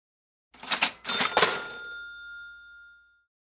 Звуки кассы
Звон кассы звучит